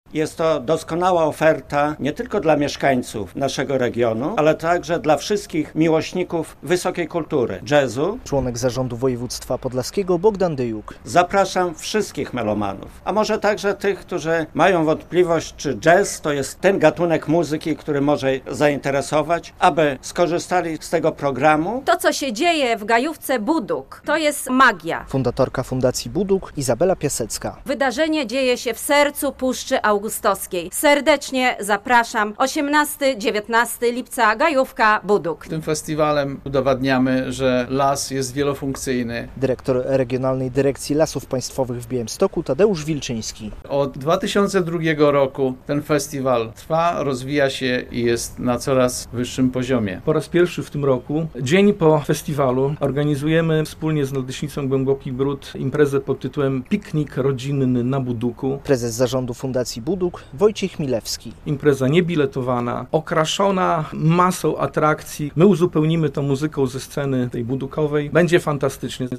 Zapowiedź 16. edycji Buduk Jazz Festiwal - relacja